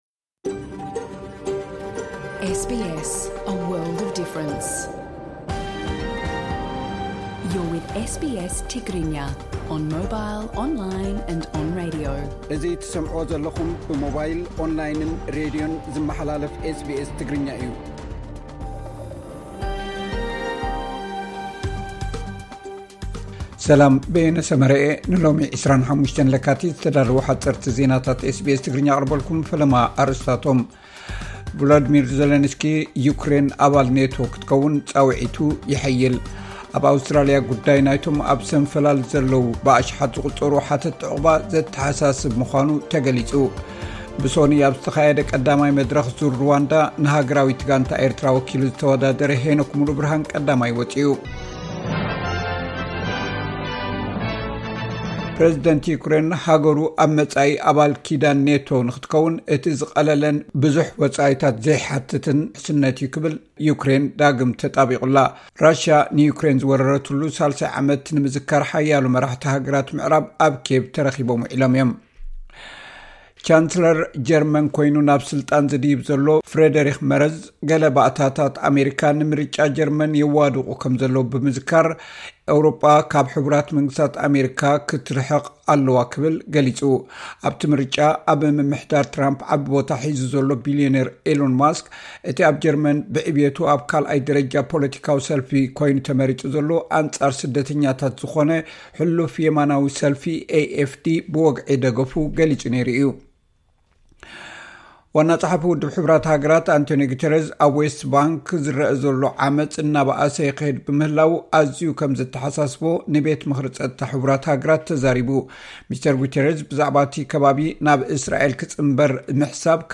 ሓጸርቲ ዜናታት ኤስ ቢ ኤስ ትግርኛ (25 ለካቲት 2025)